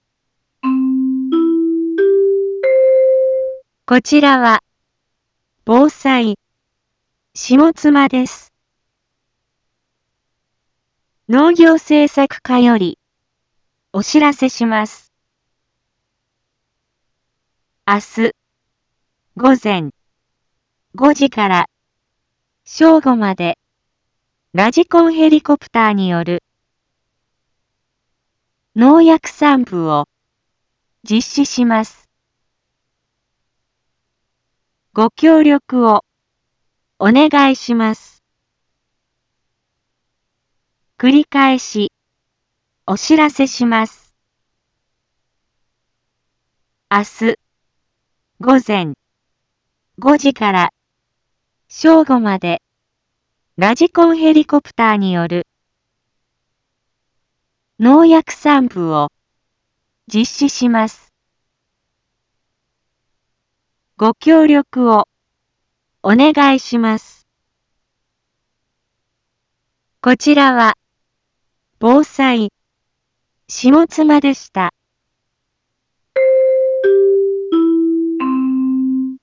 一般放送情報
Back Home 一般放送情報 音声放送 再生 一般放送情報 登録日時：2023-05-02 12:31:22 タイトル：麦のﾗｼﾞｺﾝﾍﾘによる防除（騰波ノ江） インフォメーション：こちらは、防災、下妻です。